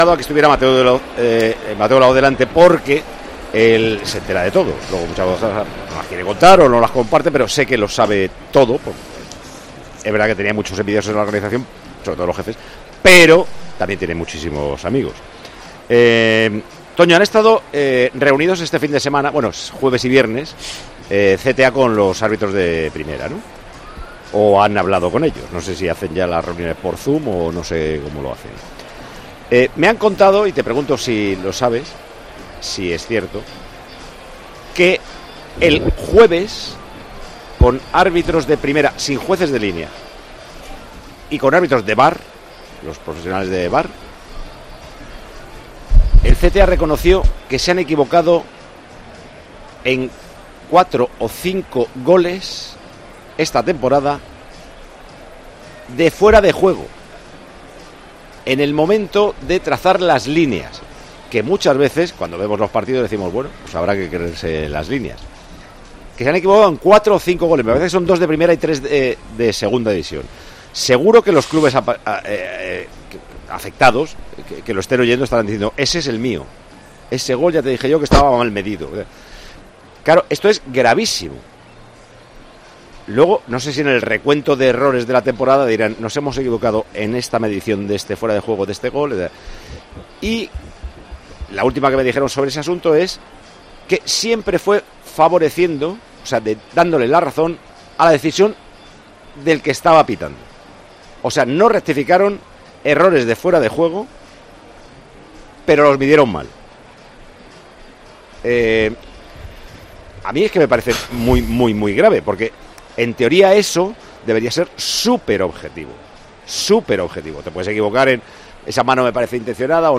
Paco González ha informado durante la retransmisión de Tiempo de Juego que el Comité Técnico de Árbitros, en una reunión con árbitros principales y de VAR, reconoció que se equivocaron en 4 ó 5 goles de fuera de juego, en el momento de trazar las líneas.